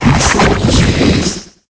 Cri de Krakos dans Pokémon Épée et Bouclier.